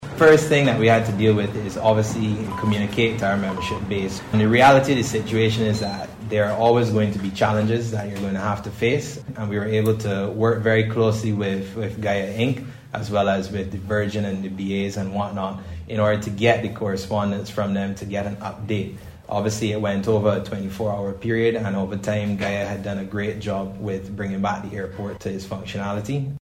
This was among the disclosures this morning during a BHTA news briefing.